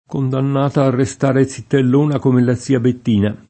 kondann#ta a rreSt#re ZZittell1na k1me lla ZZ&a bett&na] (Vamba); per l’odio covato sotto sotto dalle zitellone verso la ragazza felice [